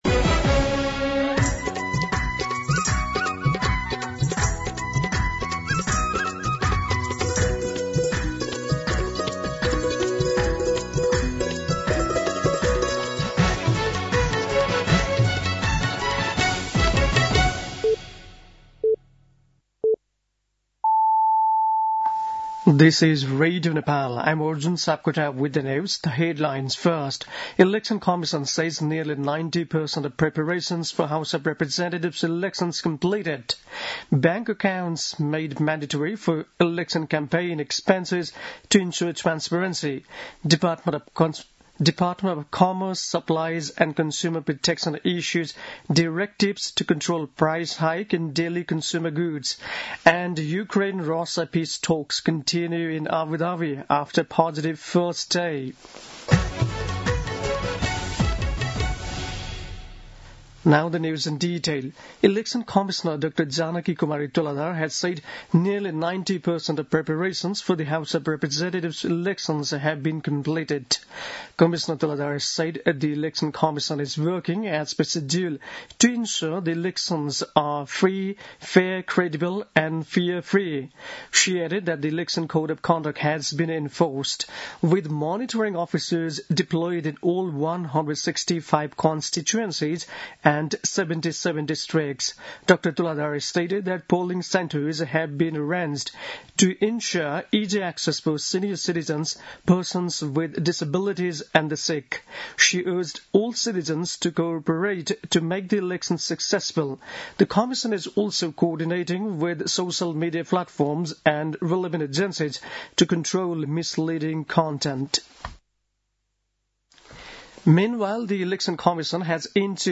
दिउँसो २ बजेको अङ्ग्रेजी समाचार : २२ माघ , २०८२